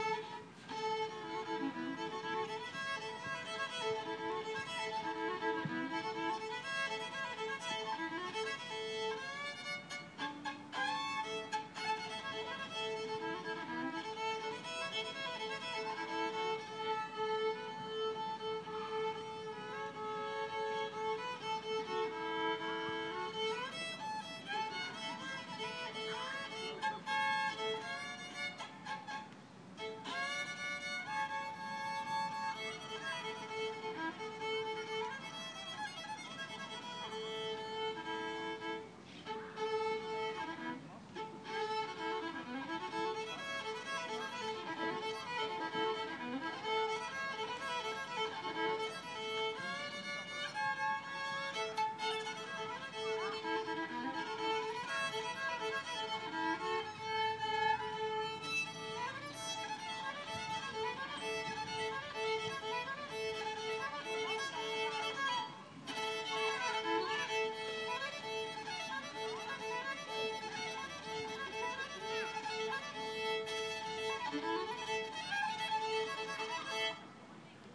Koganei violinist